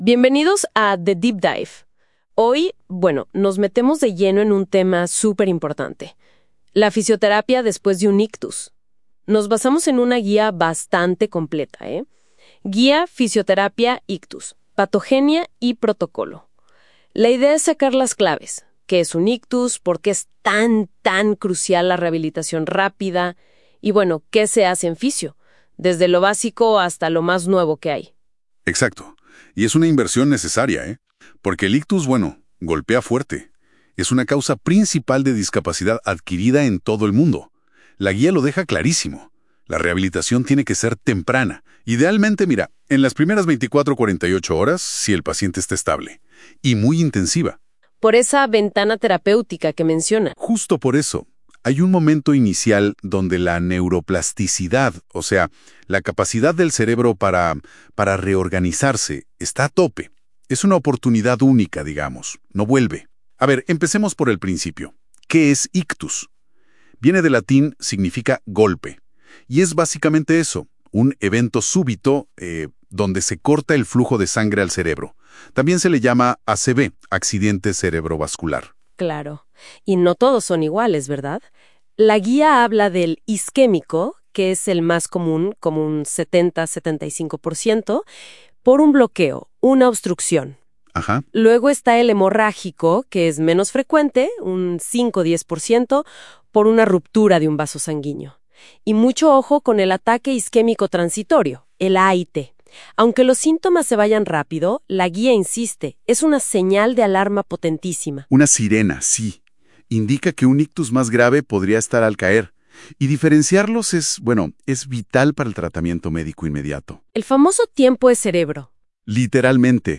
Resumen en audio